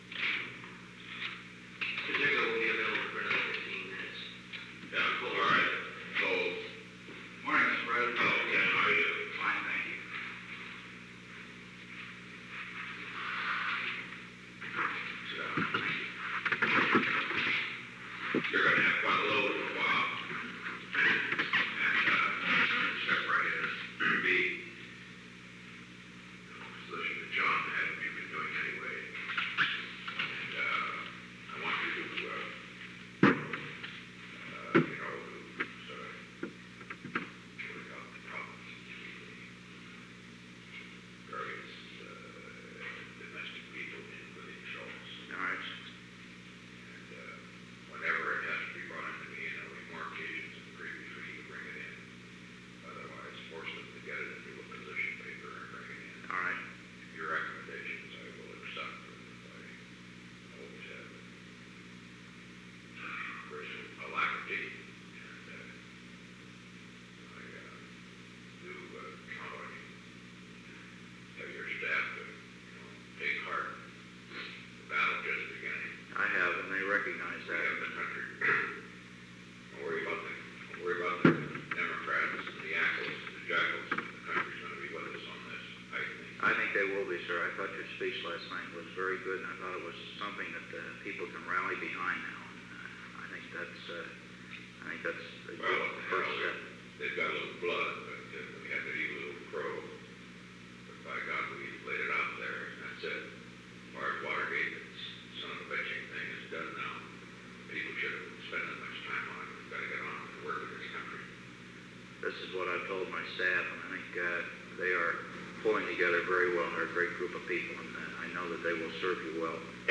Conversation No. 908-5
Location: Oval Office
The President met with an unknown man.
Kenneth R. Cole, Jr. entered and the unknown man left at 8:26 am.